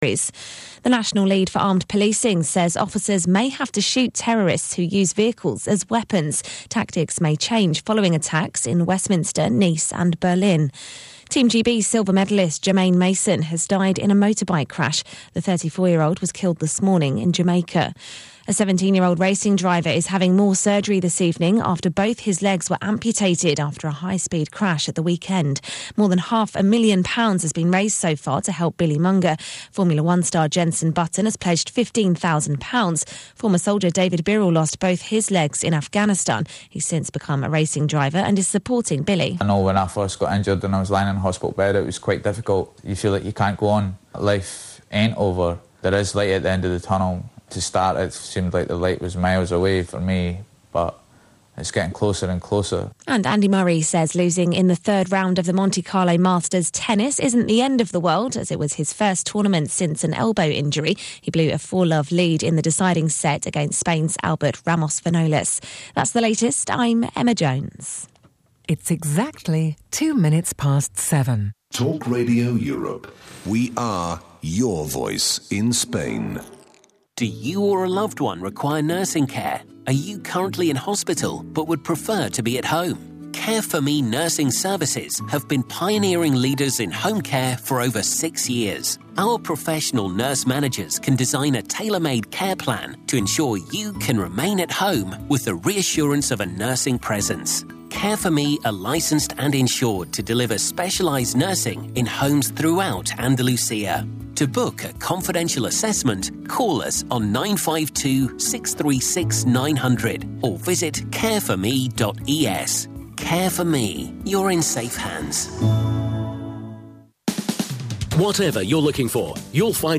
Interview on Talk Radio Europe about Israel denying me entry to Palestine